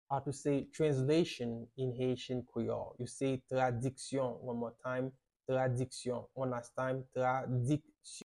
How to say "Translation" in Haitian Creole - "Tradiksyon" pronunciation by a native Haitian Teacher
“Tradiksyon” Pronunciation in Haitian Creole by a native Haitian can be heard in the audio here or in the video below:
How-to-say-Translation-in-Haitian-Creole-Tradiksyon-pronunciation-by-a-native-Haitian-Teacher.mp3